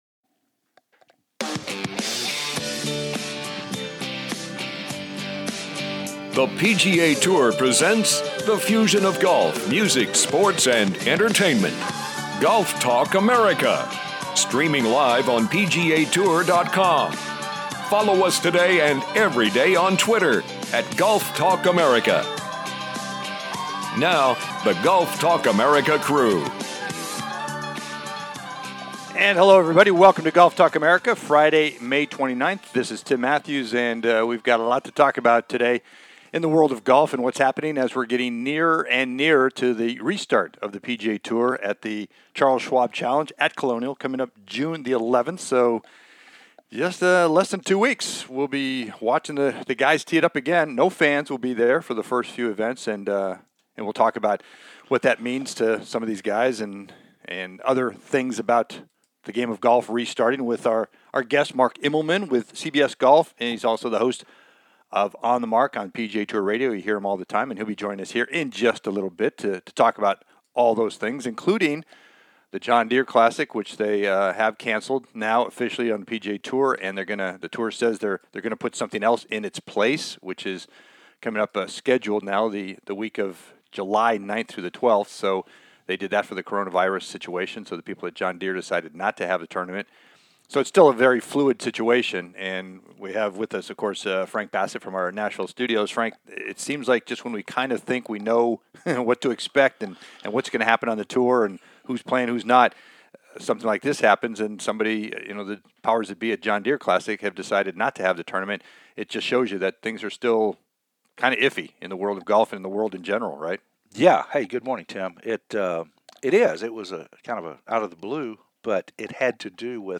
candid conversation...